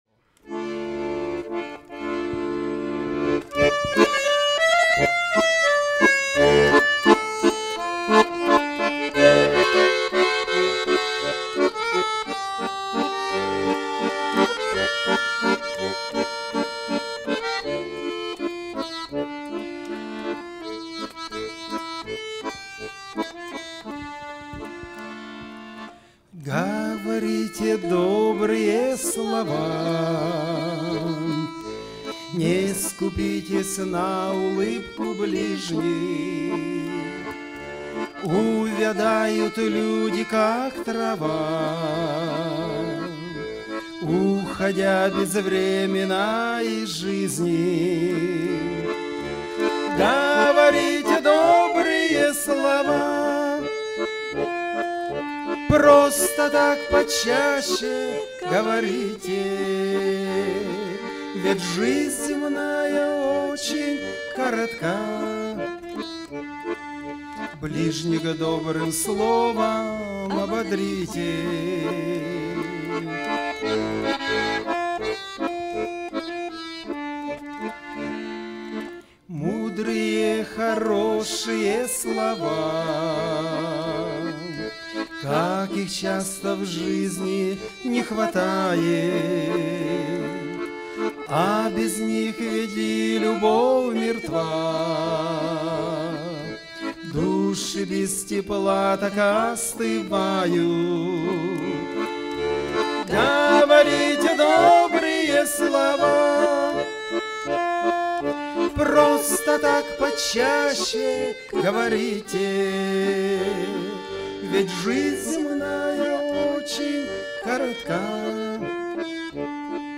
песня
954 просмотра 395 прослушиваний 56 скачиваний BPM: 83